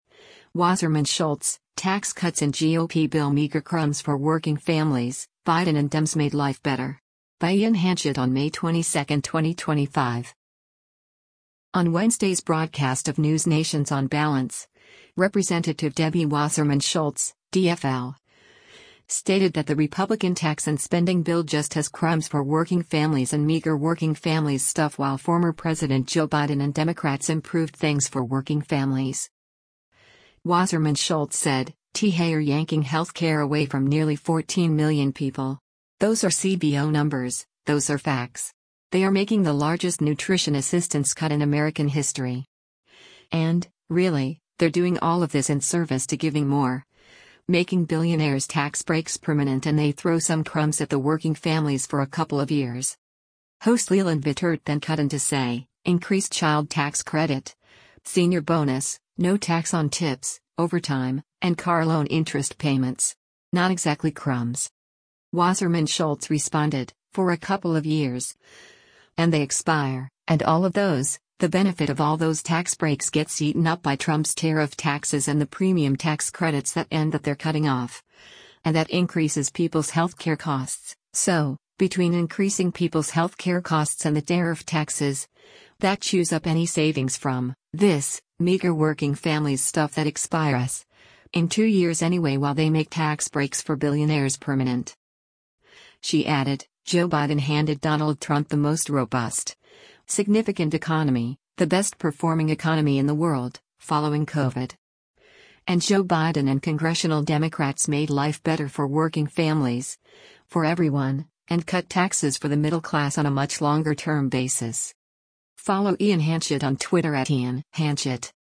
On Wednesday’s broadcast of NewsNation’s “On Balance,” Rep. Debbie Wasserman Schultz (D-FL) stated that the Republican tax and spending bill just has “crumbs” for working families and “meager working families stuff” while former President Joe Biden and Democrats improved things for working families.